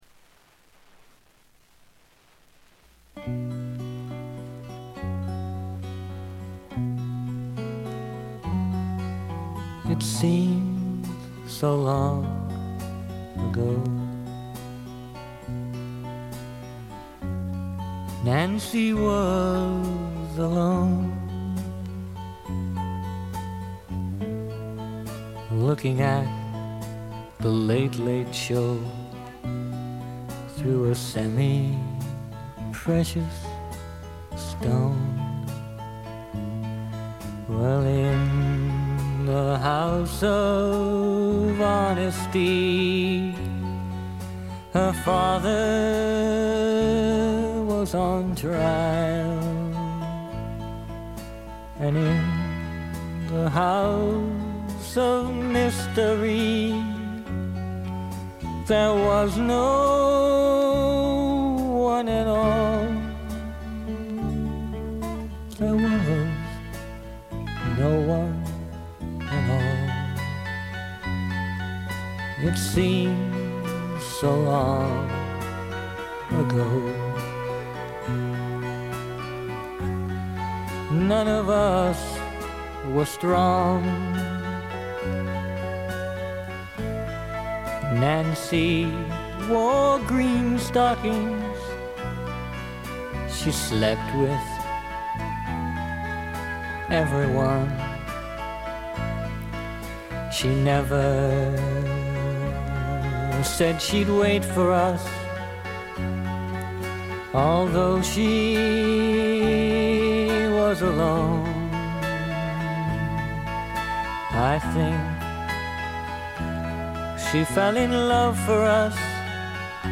静音部で低いバックグラウンドノイズ。目立つノイズはありません。
試聴曲は現品からの取り込み音源です。